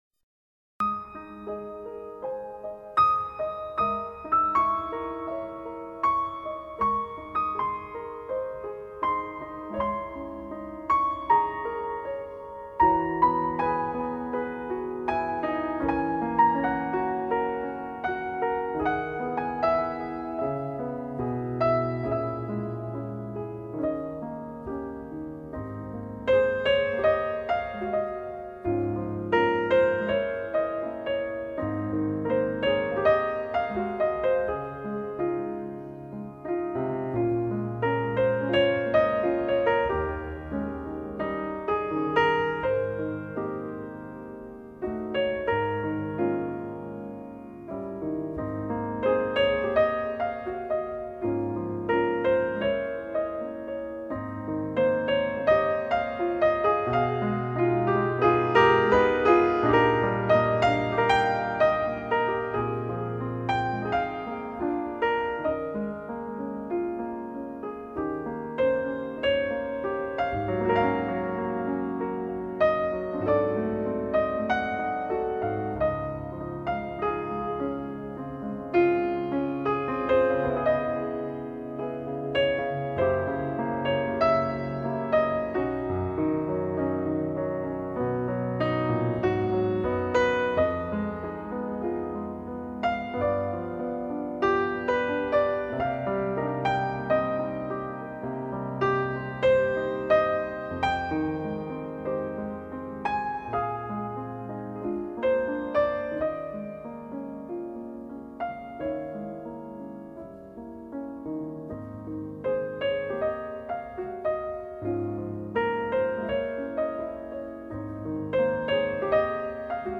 简单的音符在钢琴中化为点点滴滴的流水轻轻敲打在我的心头。
微妙的着色，包括小提琴，大提琴和单簧管混合着，与钢琴合奏着一个纯净而绿色的背景世界。